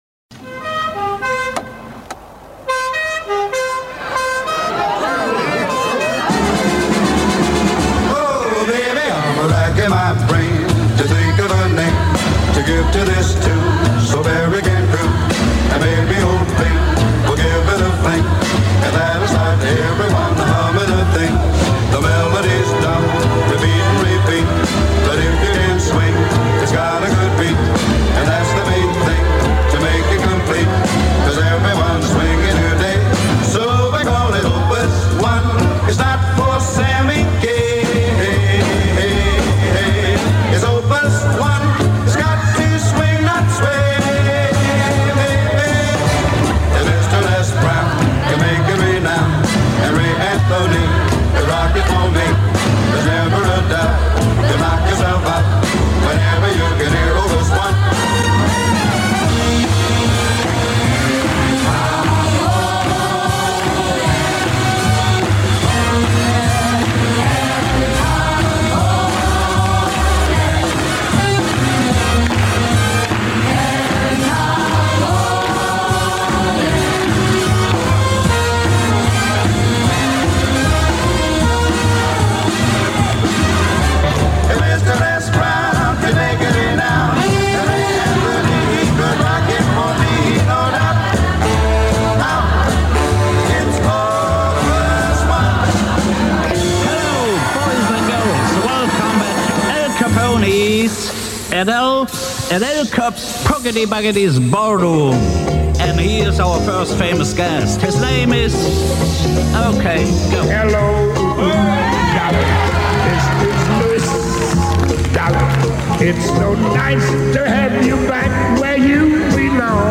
Танцевальная вечеринка в "Al Capone Ballroom" в городе Чикаго!! (нонстоп).